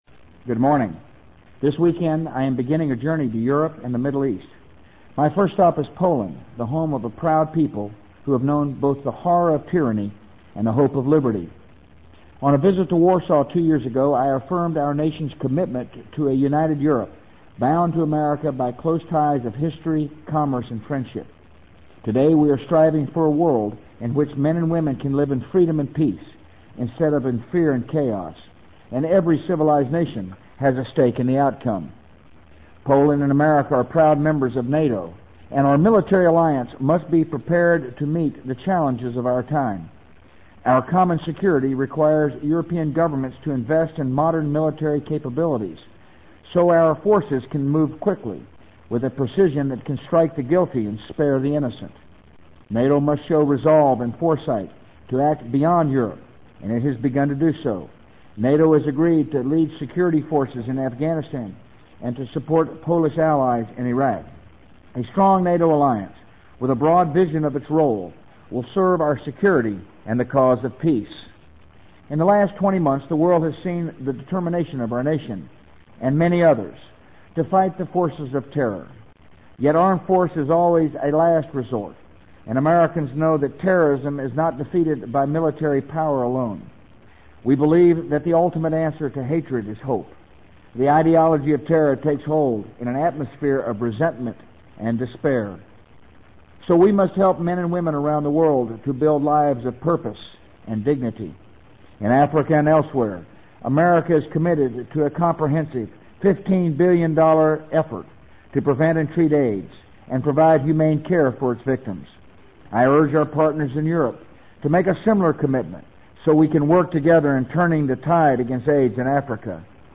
【美国总统George W. Bush电台演讲】2003-05-31 听力文件下载—在线英语听力室